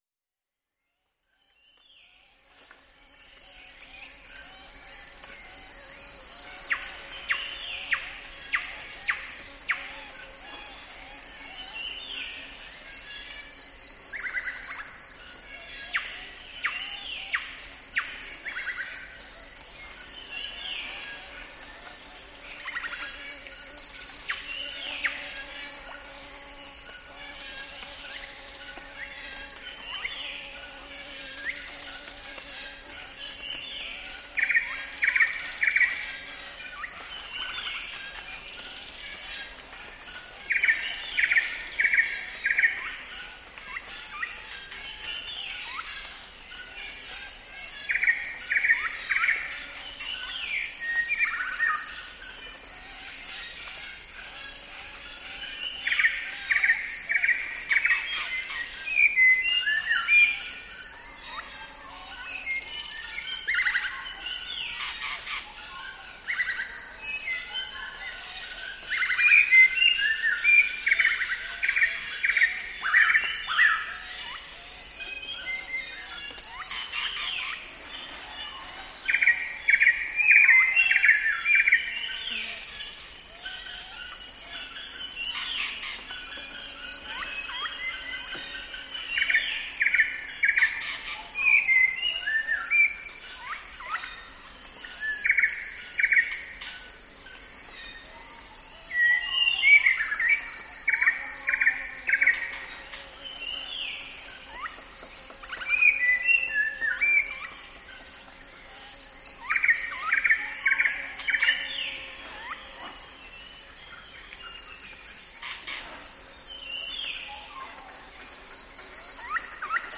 • frogs and insects in swamp.wav
frogs_and_insects_in_swamp_RZI.wav